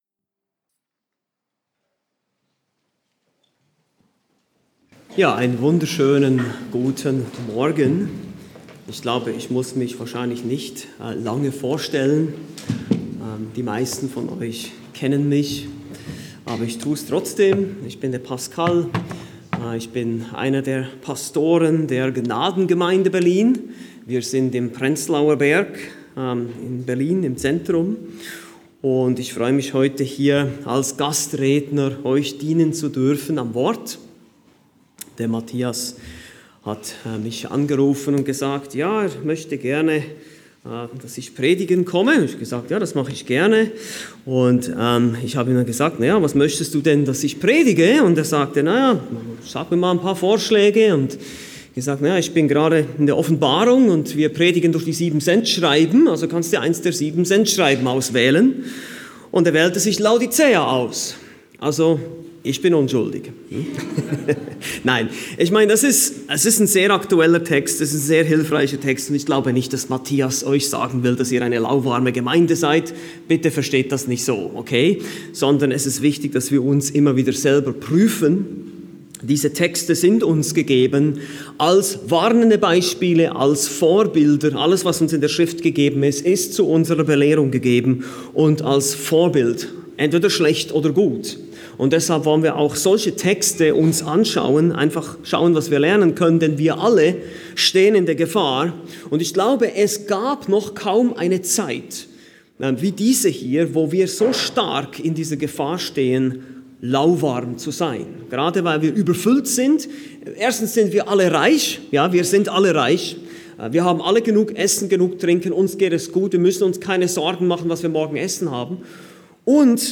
Gastprediger